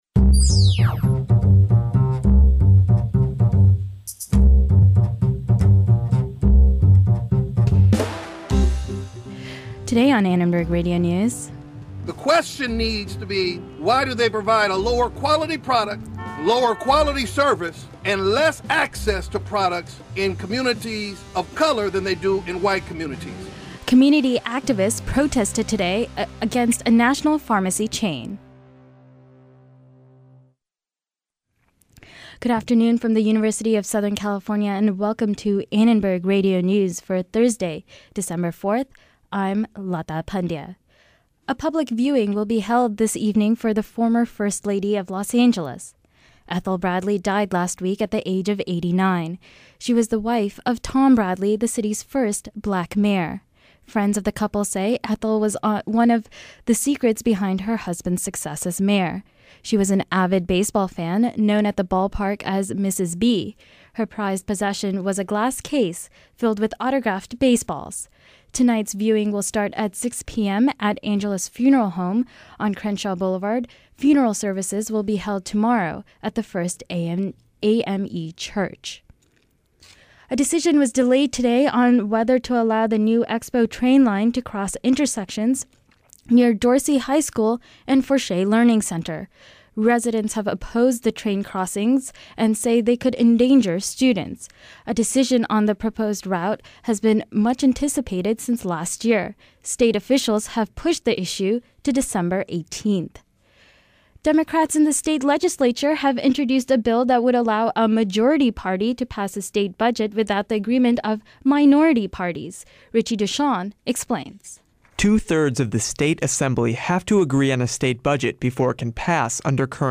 ARN Live Show - December 4, 2008 | USC Annenberg Radio News